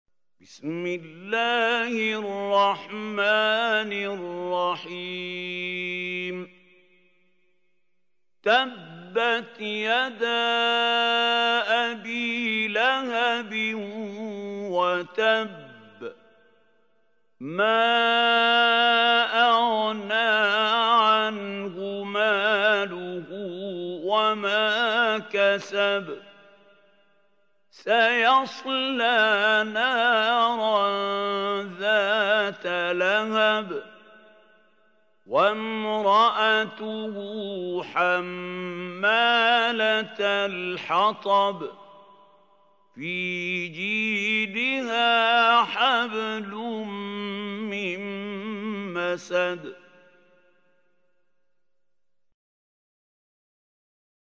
سورة المسد | القارئ محمود خليل الحصري